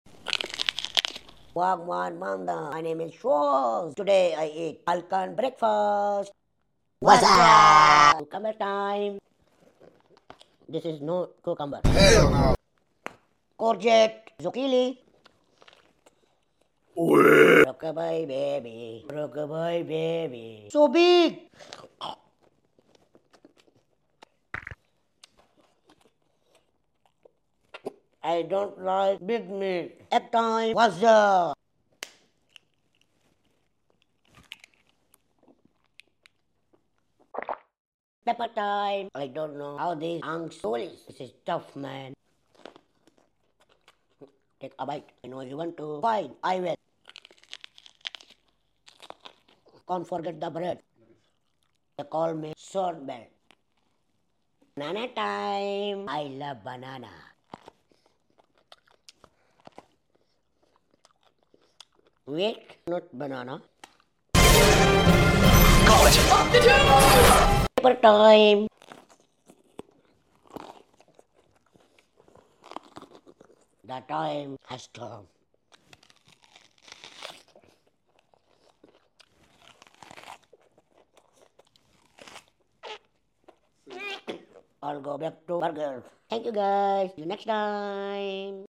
ASMR Edition. I’m not sure how I feel about the raw egg.